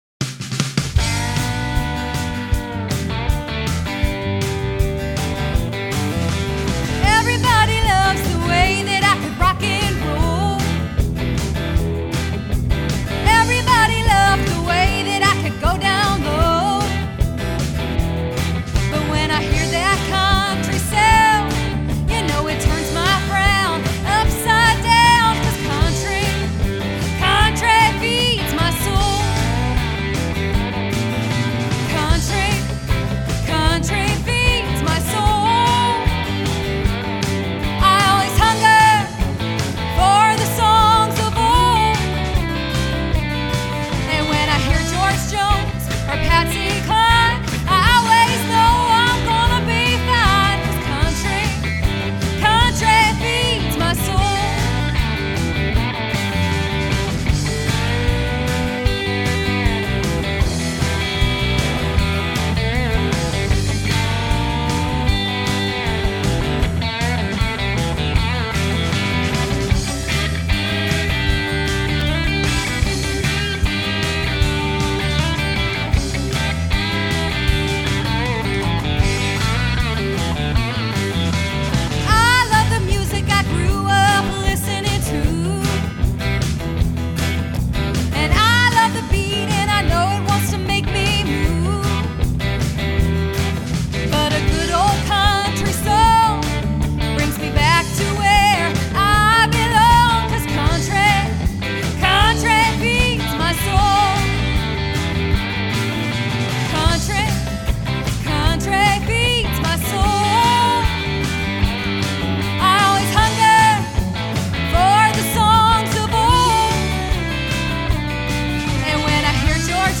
Listen to the band and me below.